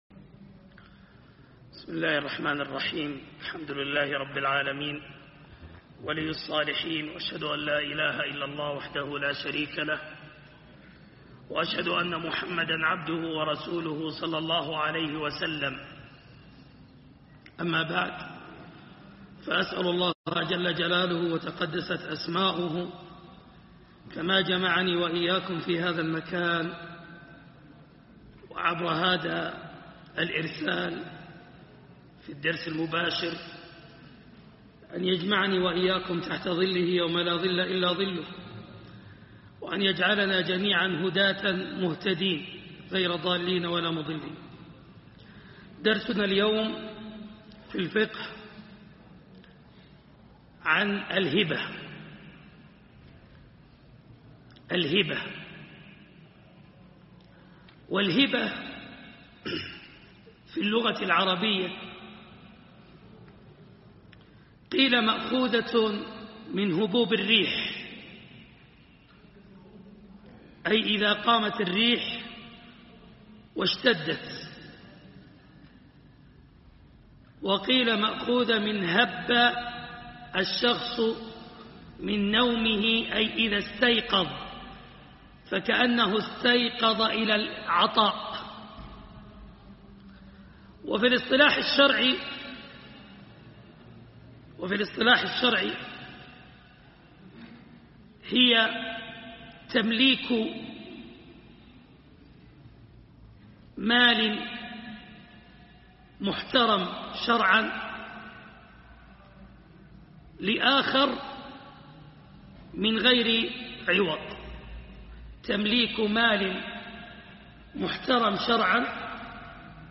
درس الفقه - الهبة